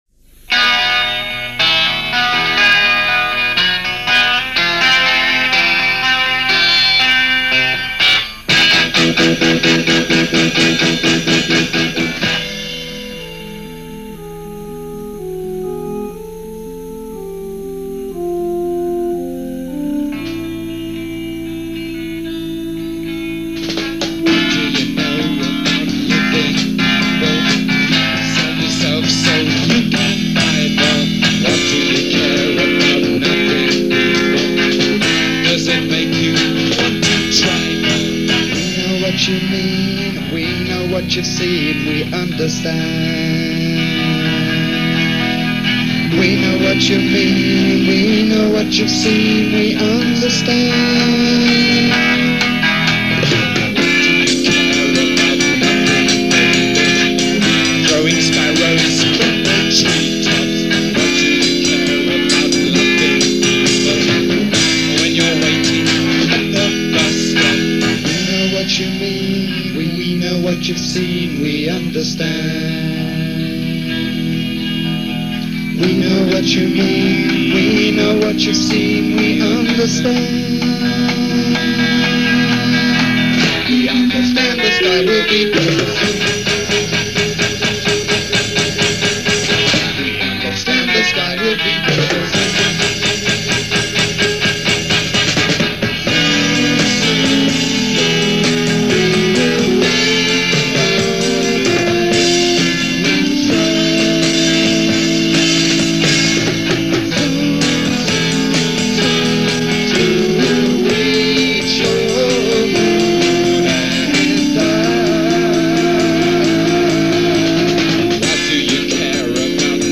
originally done for French TV from 1967 and 1968